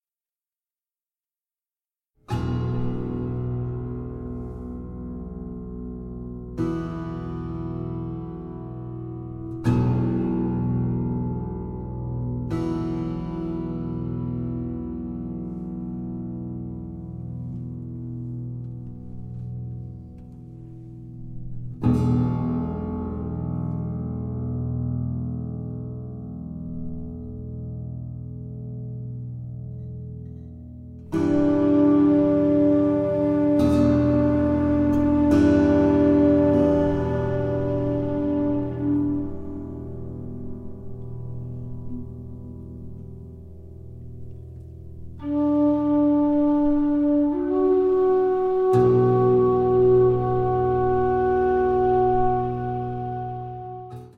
Tenor and Soprano saxophones, Alto flute, Bansuri flute